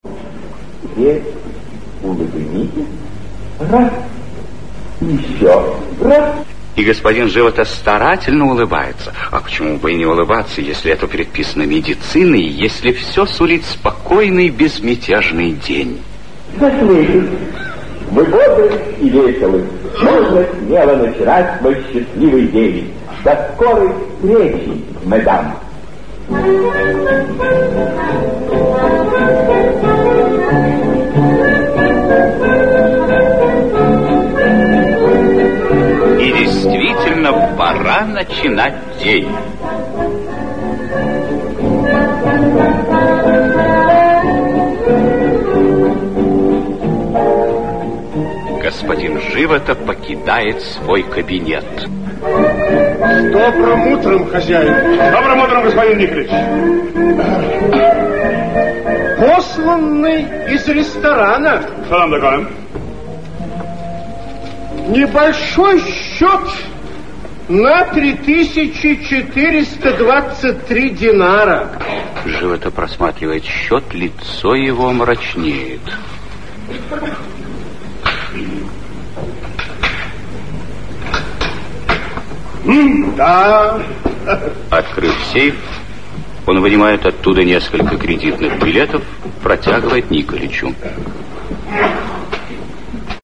Аудиокнига Доктор философии (спектакль) | Библиотека аудиокниг
Aудиокнига Доктор философии (спектакль) Автор Бранислав Нушич Читает аудиокнигу Актерский коллектив.